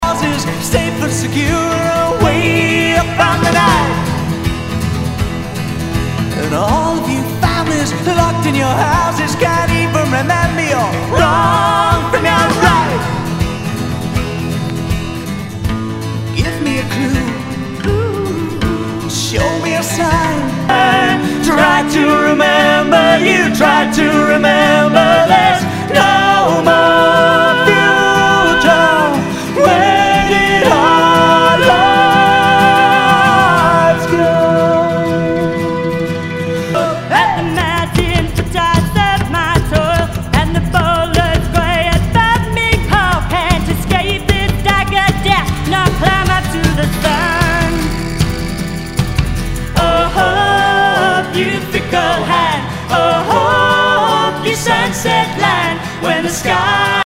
ROCK/POPS/INDIE
ナイス！フォークロック！